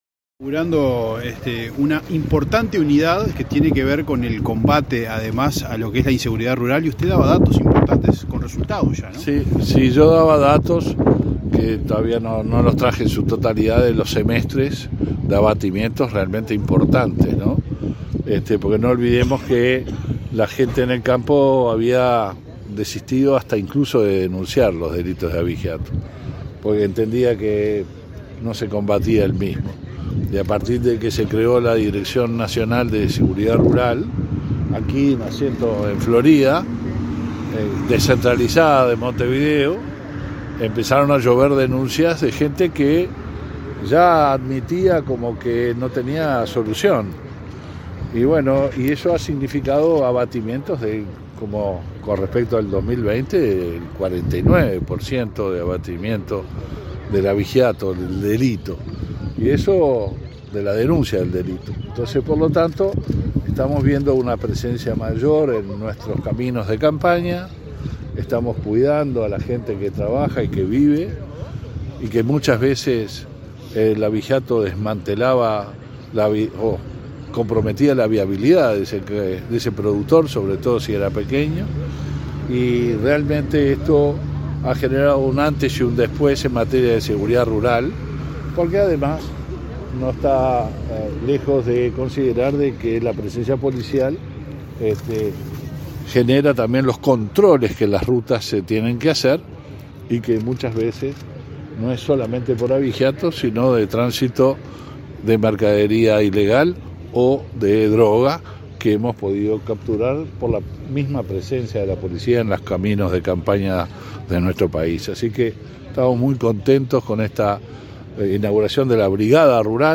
Declaraciones a la prensa del ministro del Interior, Luis Alberto Heber
Luego, dialogó con la prensa.